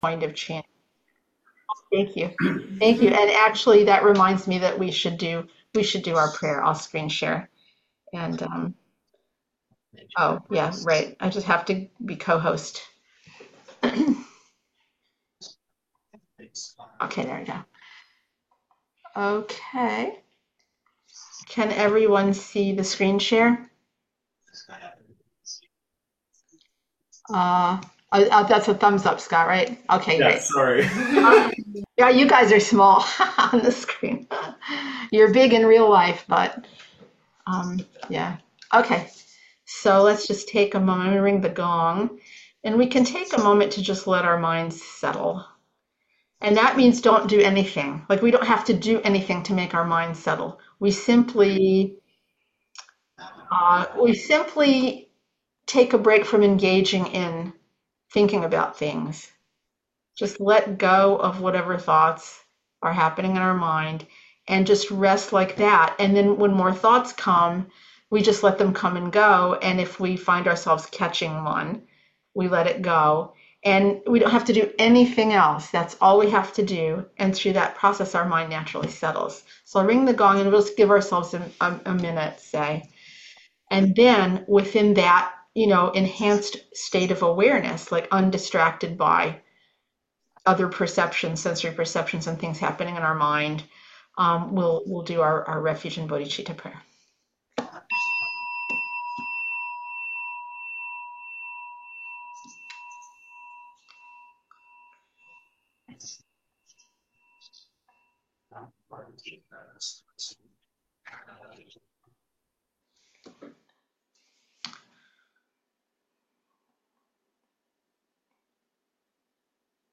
audio recording of teaching | video of teaching on youtube